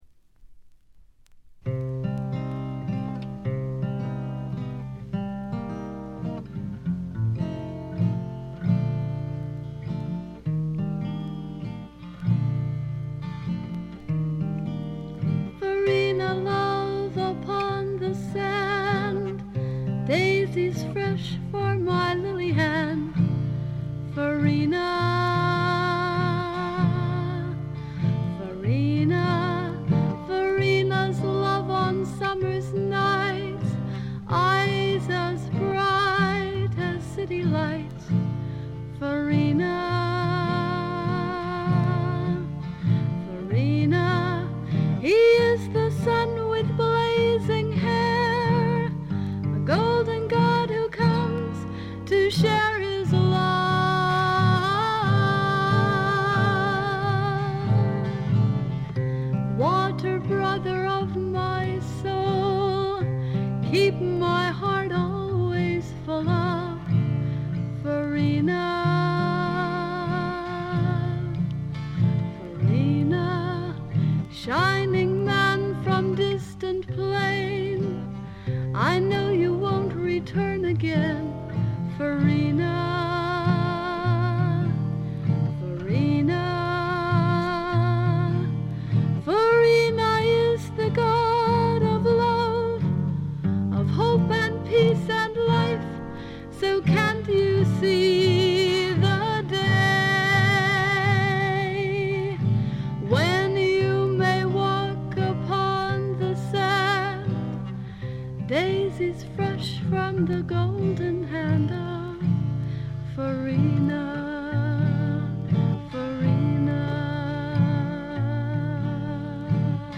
甘酸っぱい香りが胸キュンのまばゆいばかりの青春フォークの傑作。
Vocals, Guitar, Composed By ?